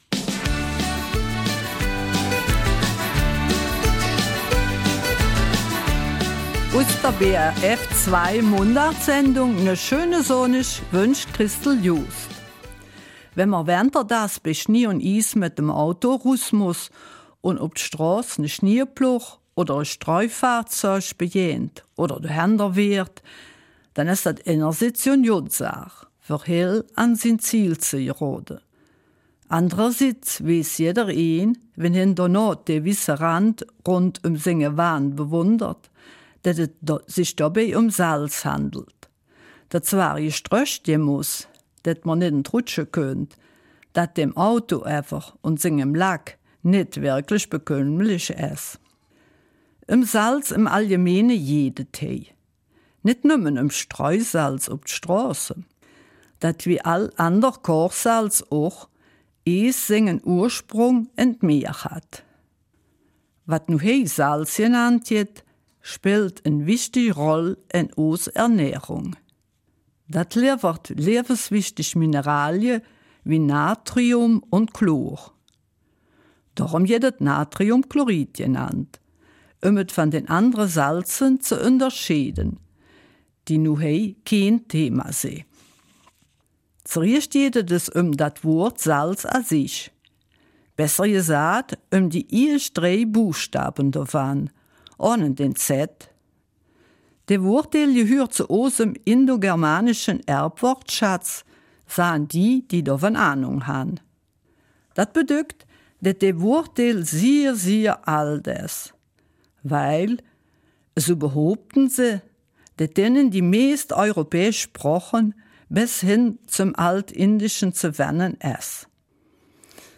Eifeler Mundart: Rund um Salz 16.